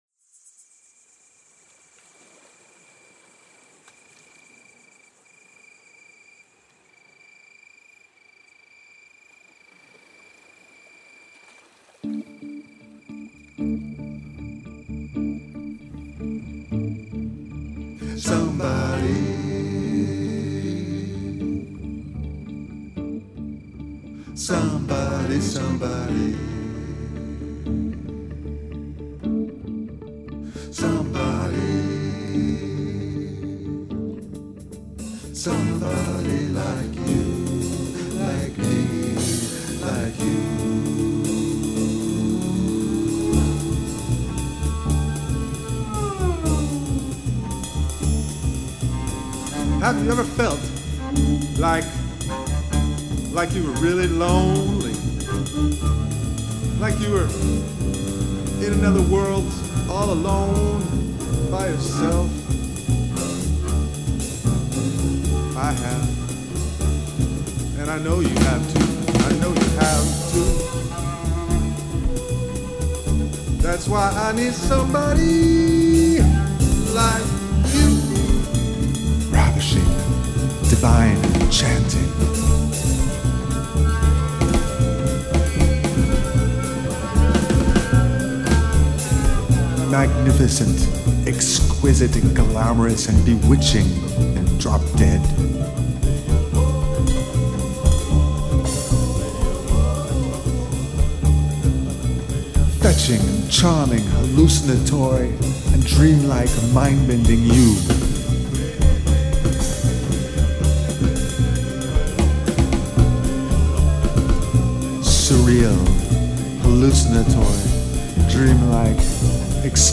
Fully recorded in my kitchen!